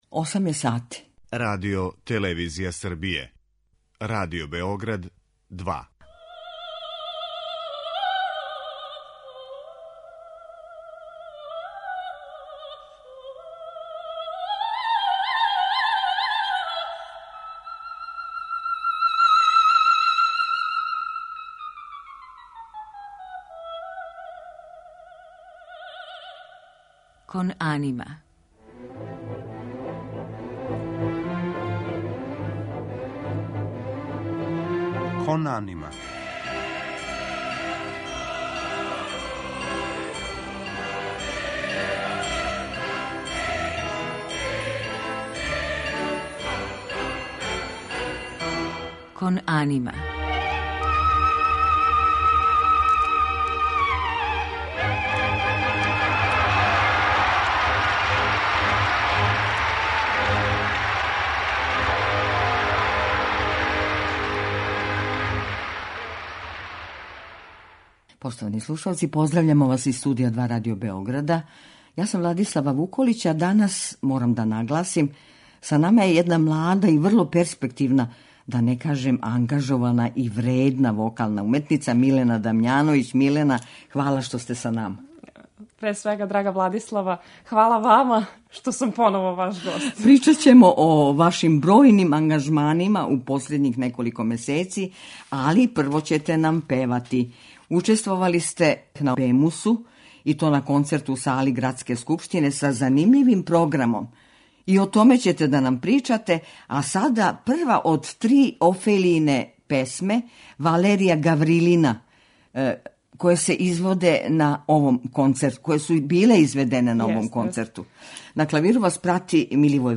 Она радо изводи соло песме, те су у њеном репертоару заступљене соло песме наших млађих композитора, као и руских аутора.